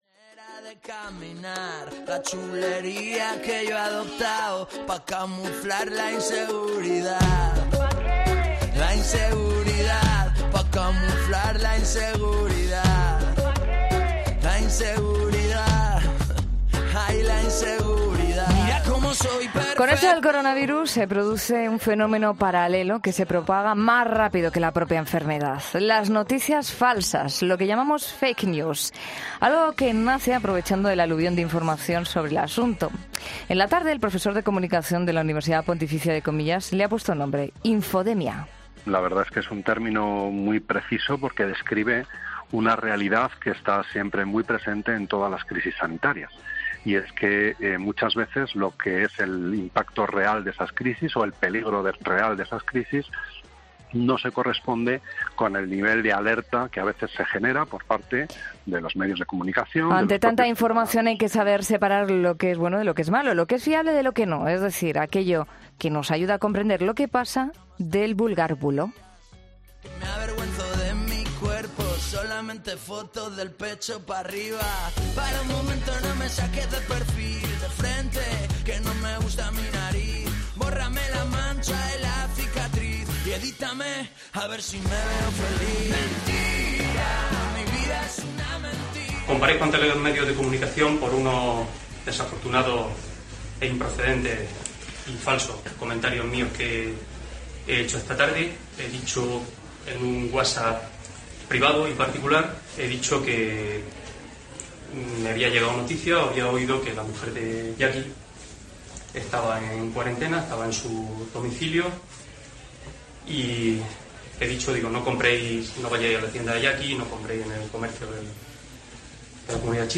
Expertos en Fake News nos hablan de este problema tan vigente en nuestra sociedad